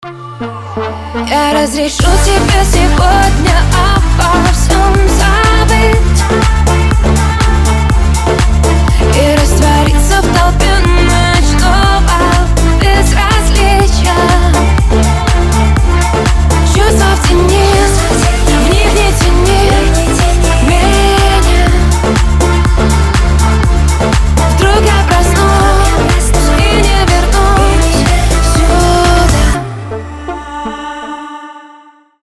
• Качество: 320, Stereo
поп
женский вокал
dance